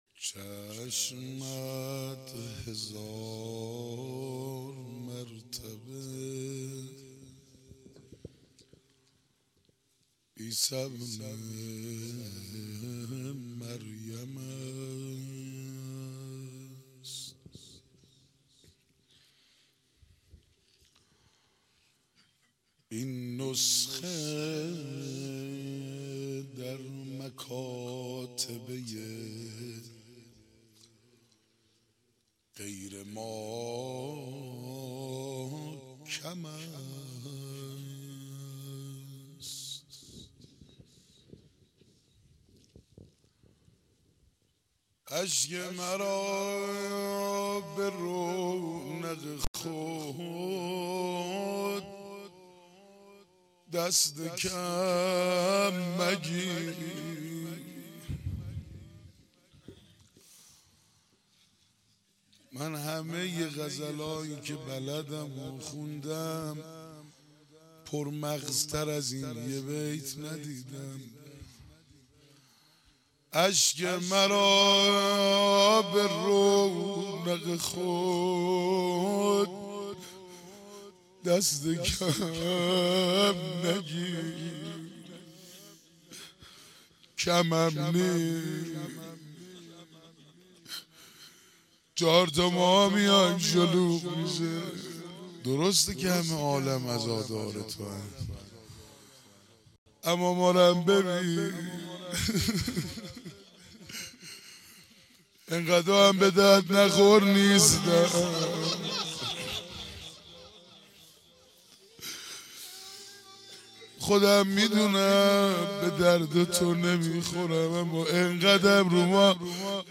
شور، زمزمه